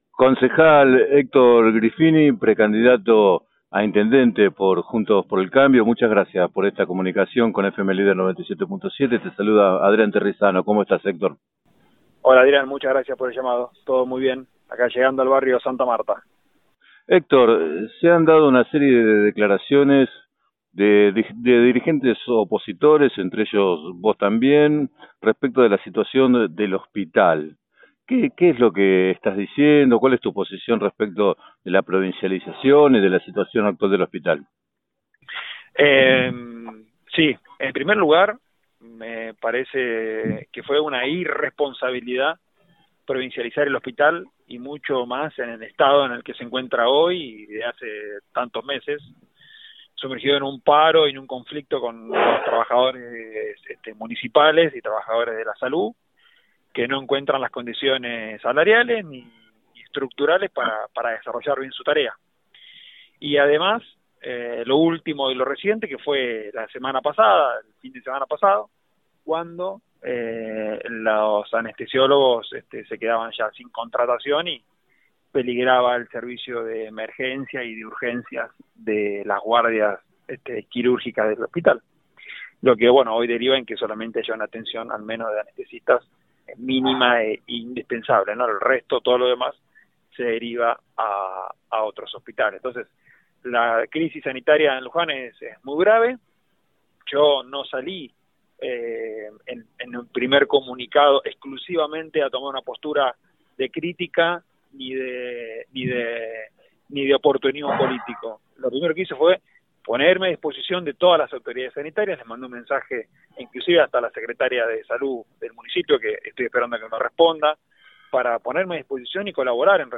En declaraciones al programa 7 a 9 de FM Líder 97.7, Griffini consideró que “la crisis sanitaria en Luján es muy grave” porque “los trabajadores no encuentran las condiciones para desarrollar su tarea” y llamó a “hacerse cargo de las decisiones que se toman”.